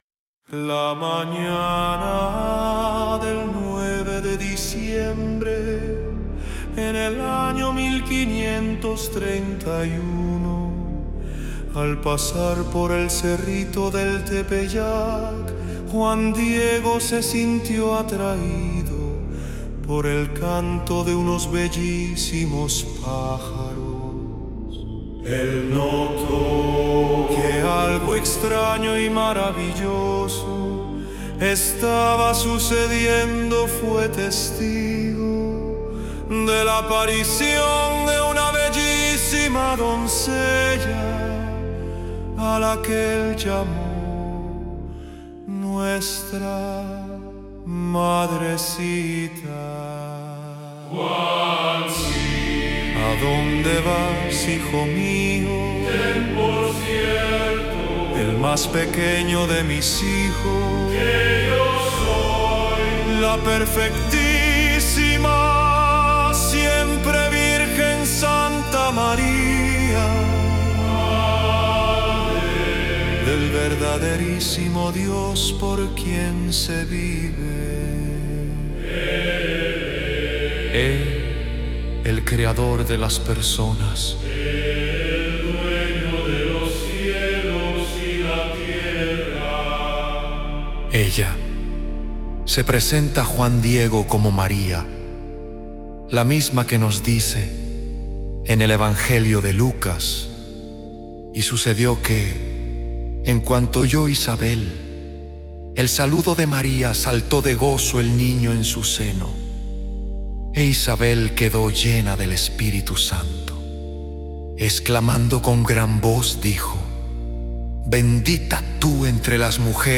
Escucha Música Muestra 4: gregoriano